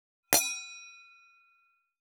323,ガラスのグラス,ウイスキー,コップ,食器,テーブル,チーン,カラン,キン,コーン,チリリン,カチン,チャリーン,クラン,カチャン,クリン,シャリン,チキン,コチン,カチコチ,チリチリ,シャキン,
コップ